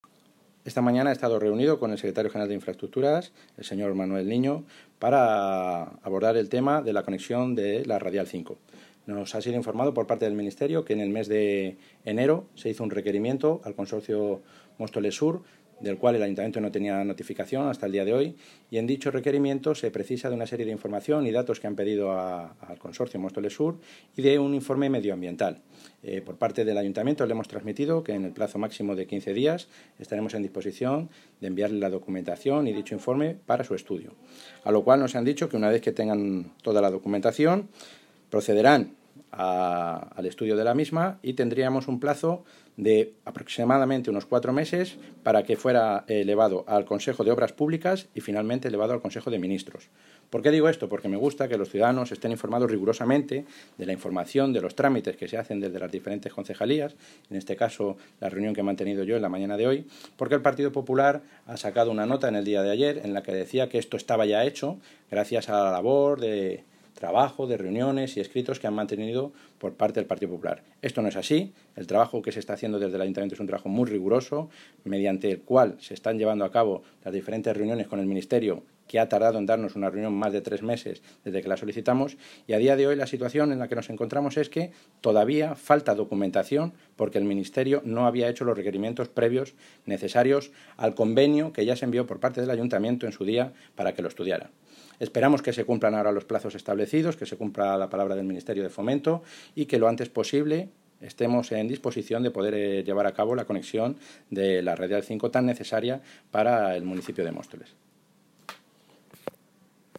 Sonido - Roberto Sánchez (Concejal de Presidencia, Seguridad Ciudadana y Comunicación)